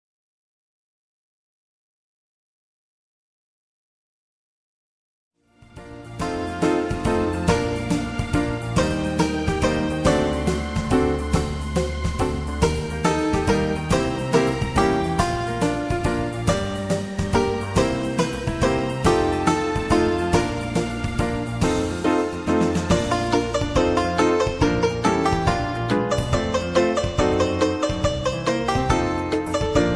Tags: backing tracks , irish songs , karaoke , sound tracks